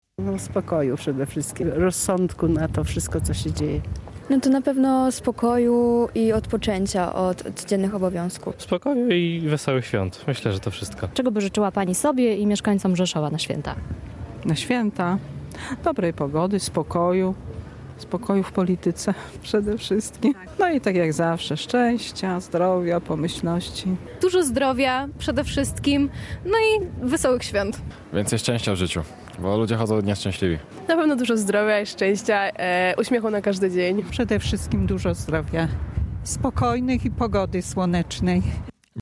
Czego najczęściej życzą sobie i innym zapytani przez nas mieszkańcy Rzeszowa?
sonda.mp3